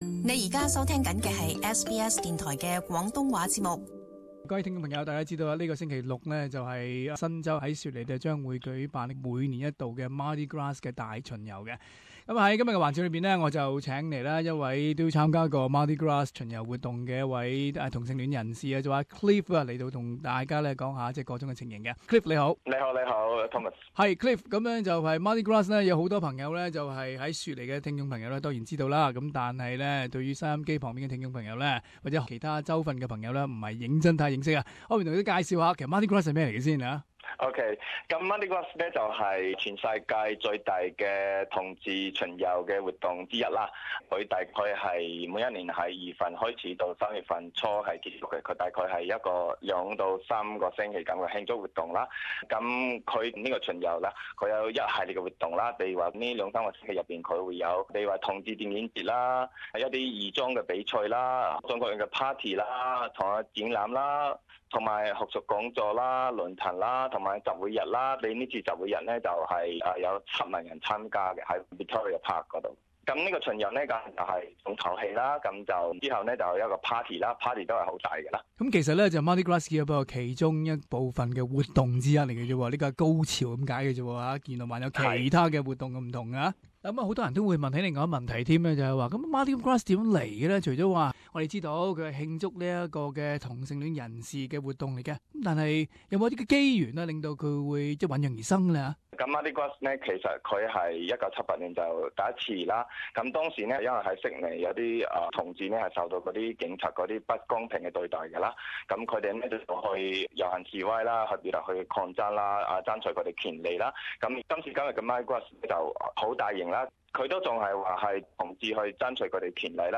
【社團專訪】同性戀團體慶祝今年Mardi Gras大巡遊活動